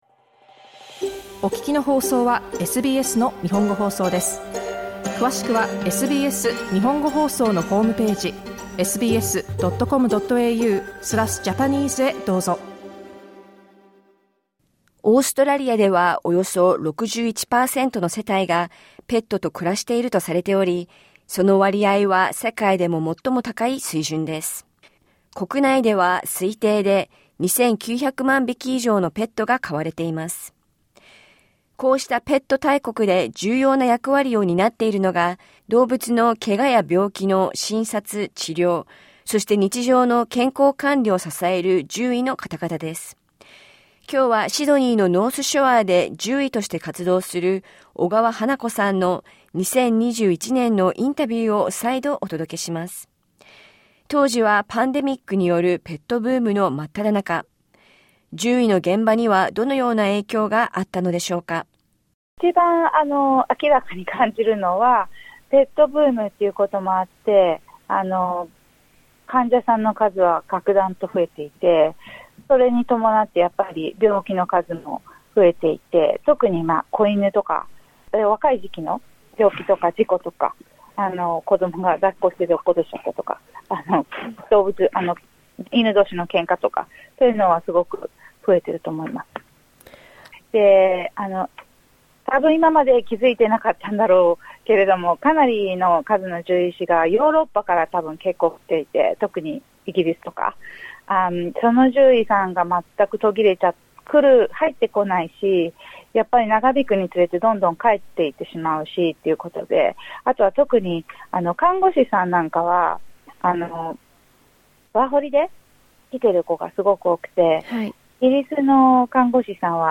インタビューでは、クリスマスシーズンにペットで気をつけたいこと、事故を防ぐポイントななども聞きました。